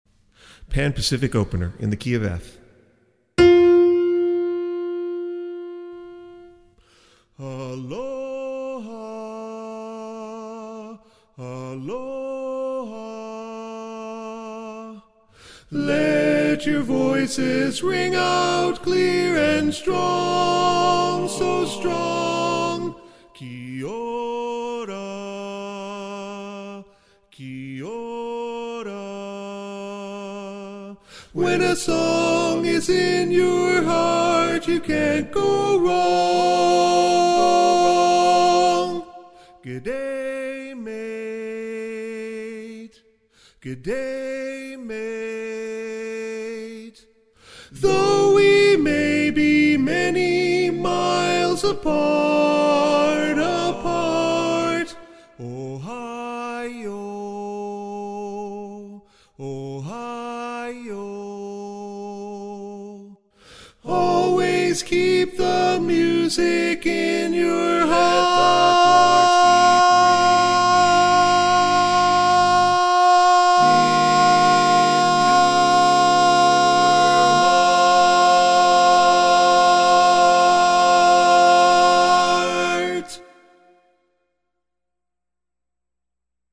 PPOTenor.mp3